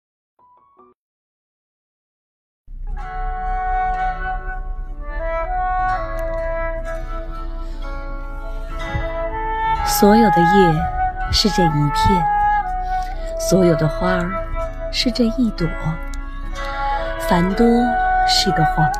九年级语文下册3《统一》女生配乐朗读（音频素材）